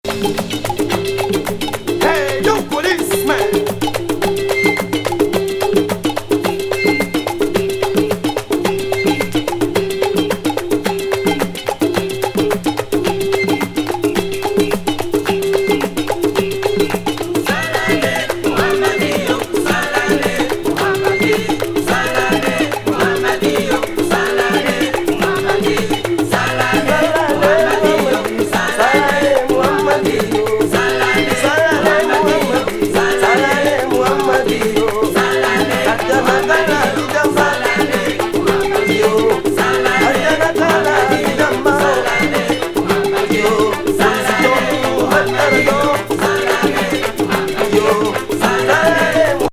アフロ・ファンク～フュージョン